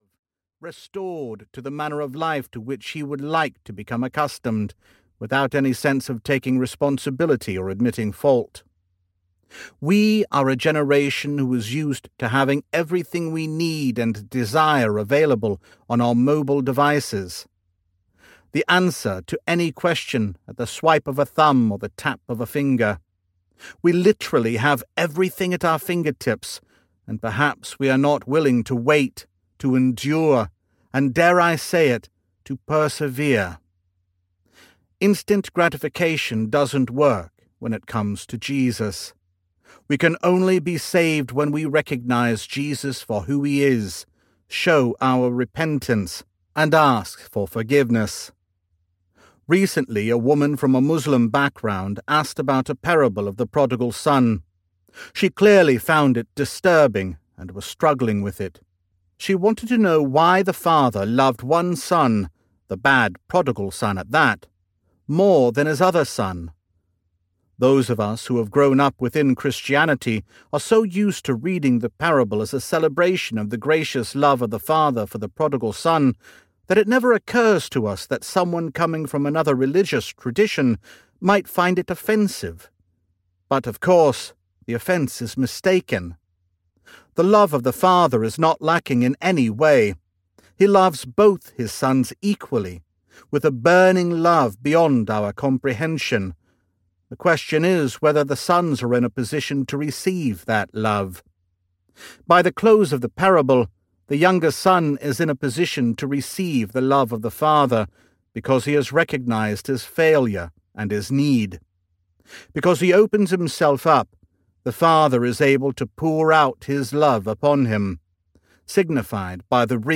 Strange Kingdom Audiobook